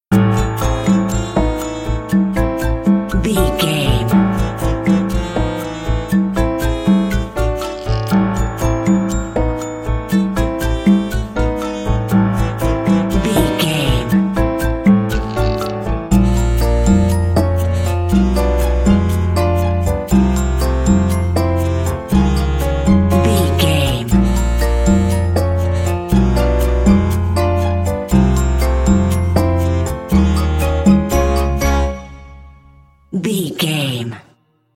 Aeolian/Minor
piano
percussion
flute
silly
circus
goofy
comical
cheerful
perky
Light hearted
quirky